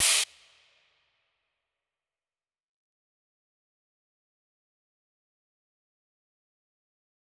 Crashes & Cymbals
DMV3_Crash 2.wav